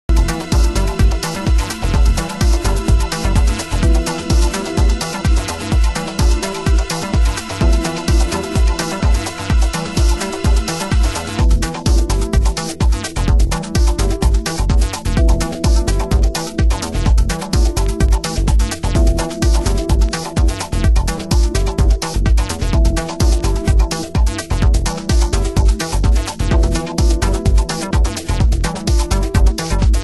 オリジナリティのあるサウンドメイク＆リズム構成のエレクトリックJAZZ/FUNK！